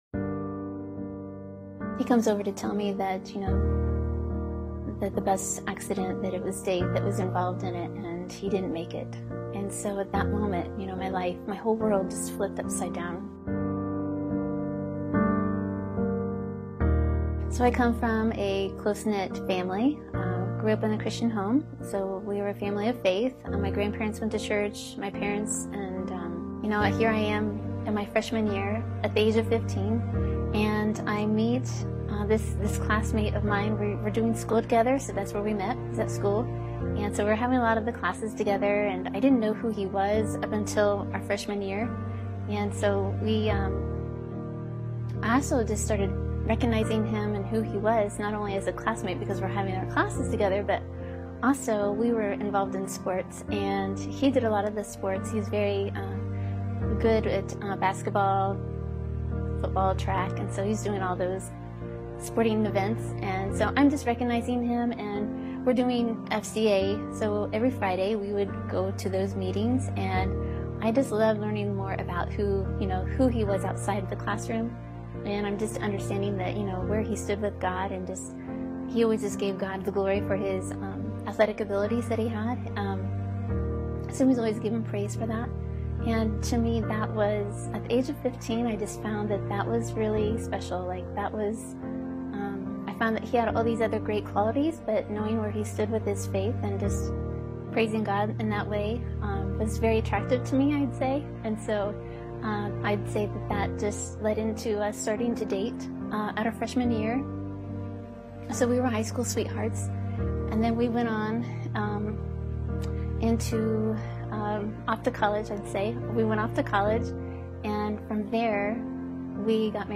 gives her testimony of how God's brought peace in her life even out of heartbreaking loss.